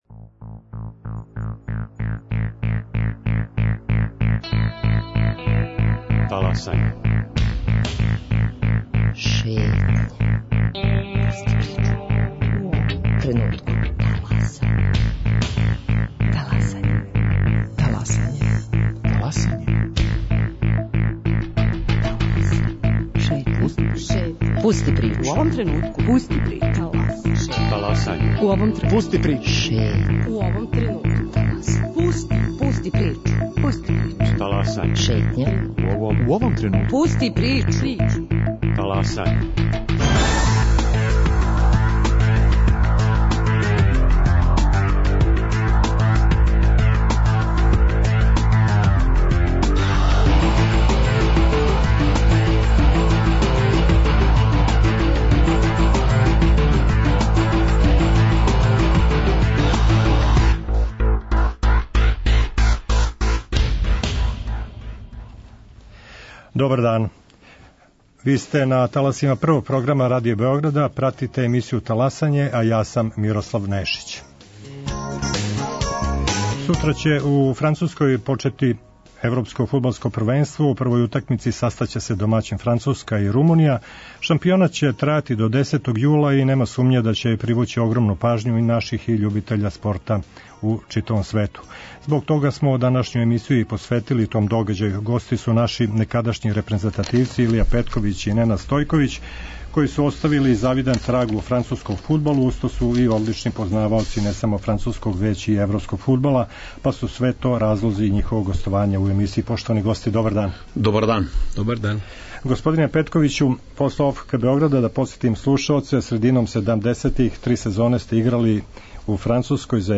Тема емисије биће Европско фудблско првенство које ће почети у петак утакмицом домаћина Француске и селекције Румуније. О спортском делу овог догађаја: фаворитима, ко би могао пријатно да изненади, да ли ће Шпанија остати у врху или Немачка објединити светску и европску титулу, да ли је повећање броја учесника са 16 на 24 добро или лоше, због чега наш државни тим пропушта и ово велико такмичење… говориће некадашњи репрезентативци Илија Петковић и Ненад Стојковић који су оставили видан траг и у француском фудбалу.